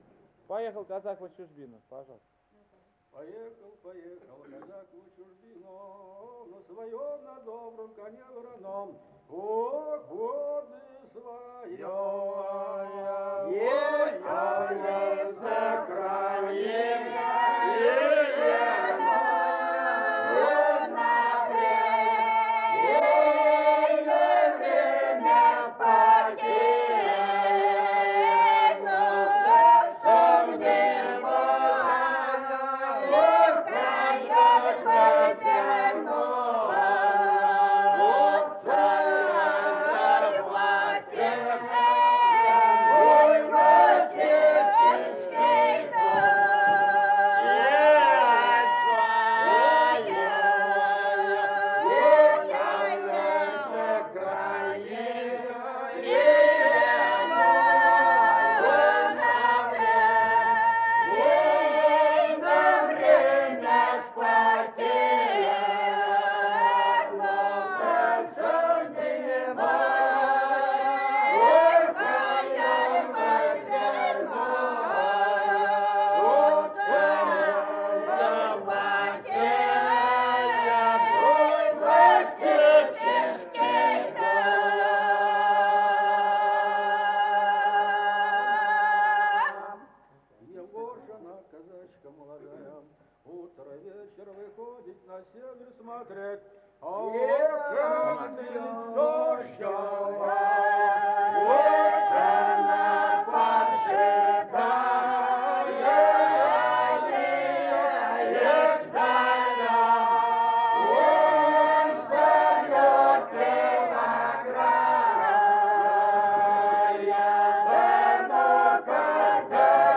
Традиция протяжного пения хоперских казаков станицы Слащевская Кумылженского района Волгоградской области
Протяжная воинская лирическая песня
в исполнении фольклорного ансамбля станицы Слащевская Кумылженского р-на Волгоградской обл.
Место фиксации: Волгоградская область, Кумылженский район, станица Слащевская Год